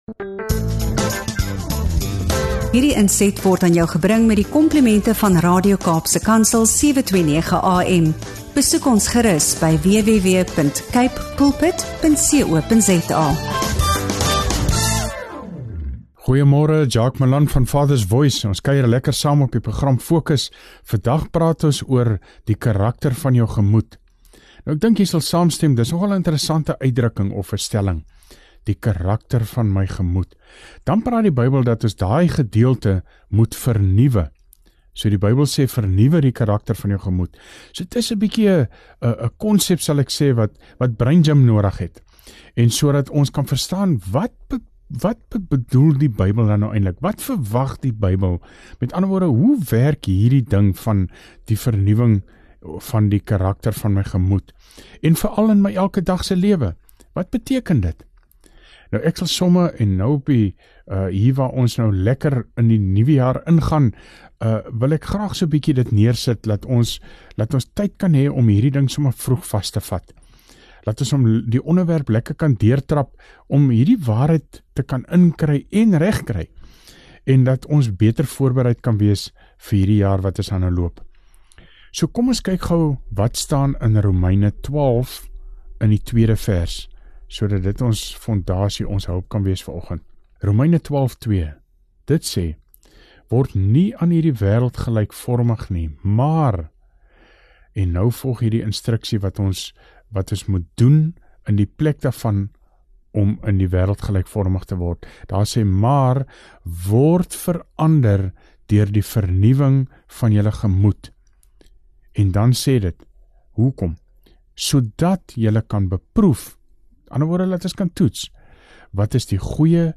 Hierdie gesprek help jou om te verstaan hoe ’n wedergebore lewe vra vir ’n nuwe denkwyse, nuwe fokus en nuwe leefstyl.